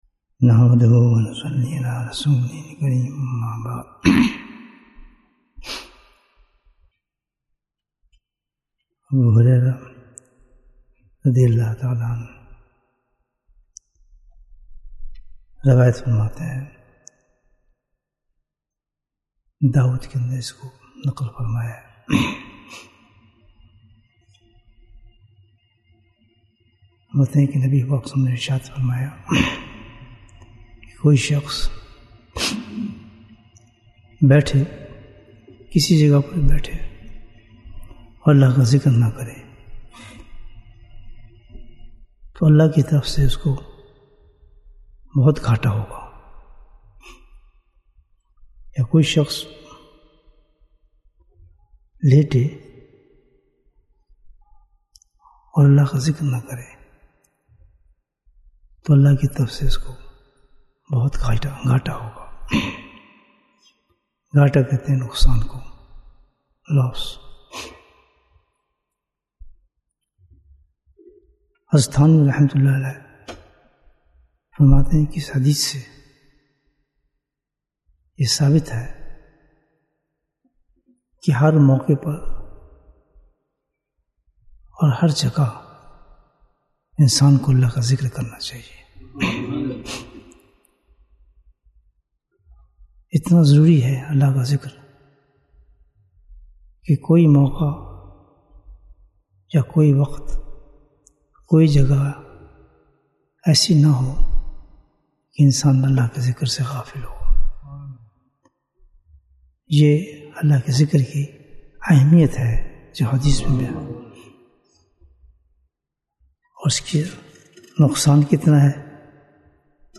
Bayan, 22 minutes30th May, 2023